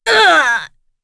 Morrah-Vox_Damage_02.wav